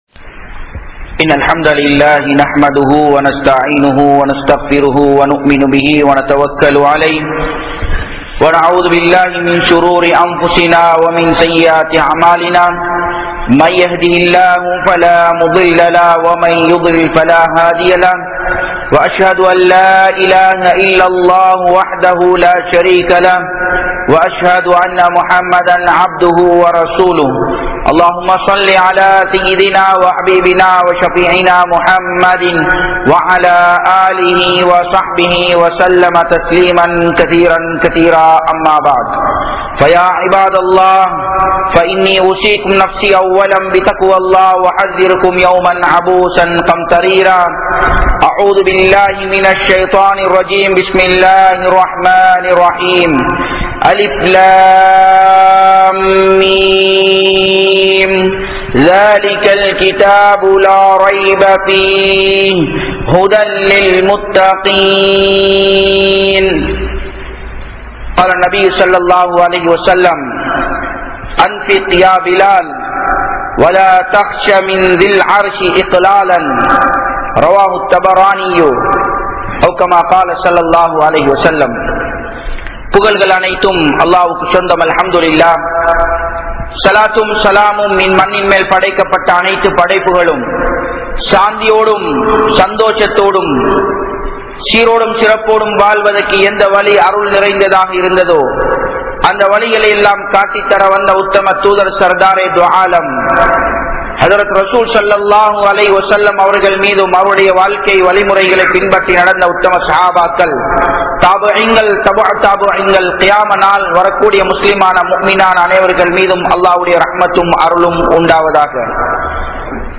Thaqwa (தக்வா) | Audio Bayans | All Ceylon Muslim Youth Community | Addalaichenai